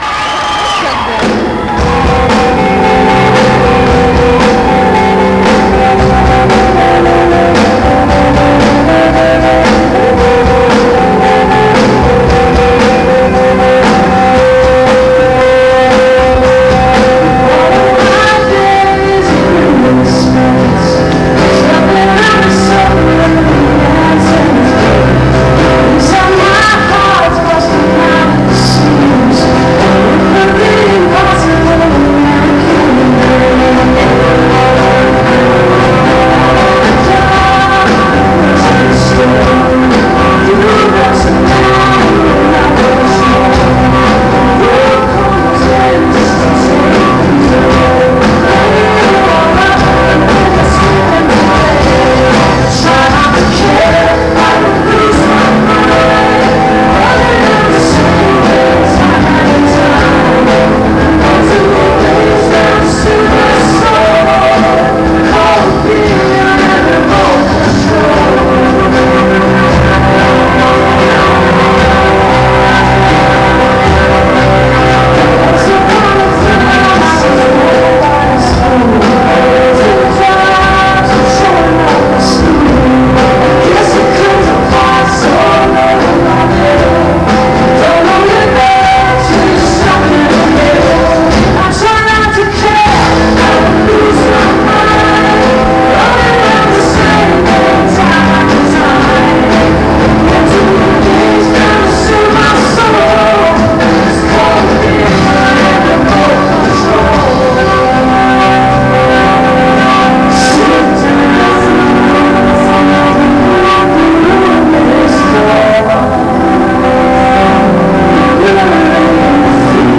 (this recording has some distortion)